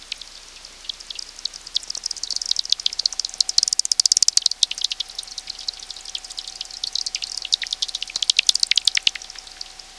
日が暮れる頃、近所の池のある公園で、本機がガンガン反応しました。
batdet.wav